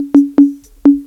PERC LOOP1-R.wav